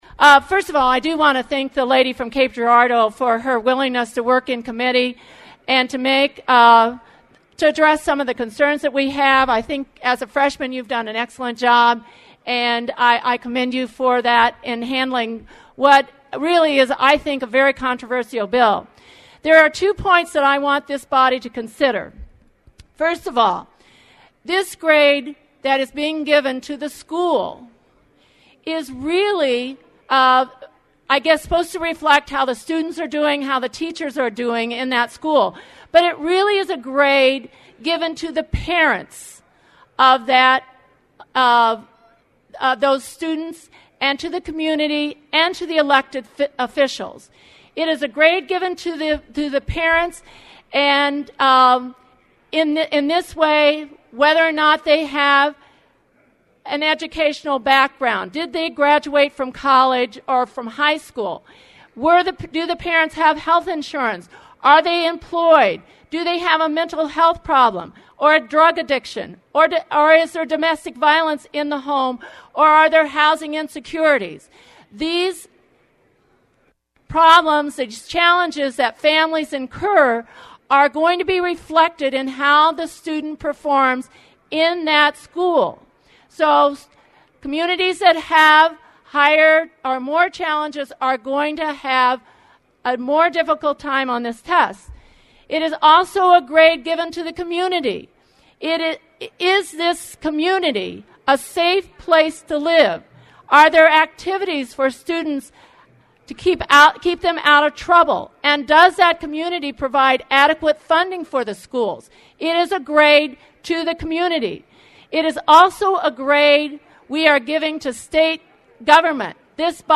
AUDIO:  Margo McNeil opposes HB 388, 4:58